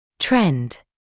[trend][трэнд]тенденция, тренд; иметь тенденцию